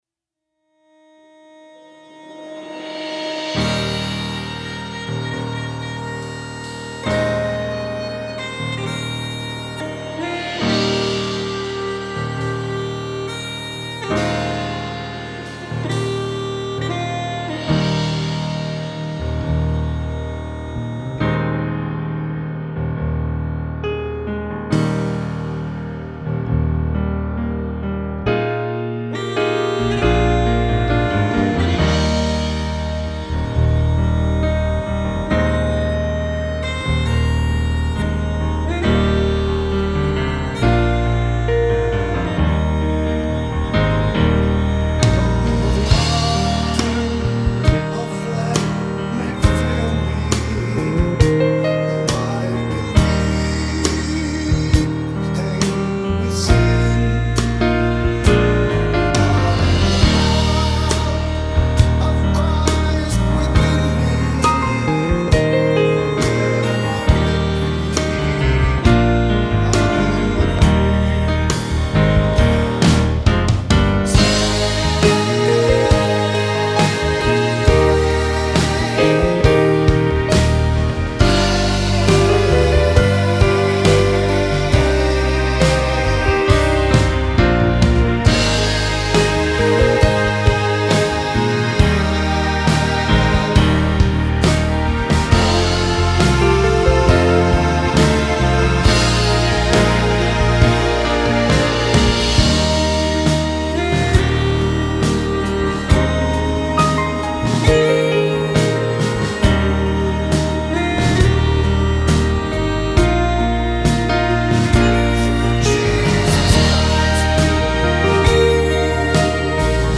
NoLeadVocal   II Corinthians 12:9-10 Folk My Confession Piano, strings, bagpipe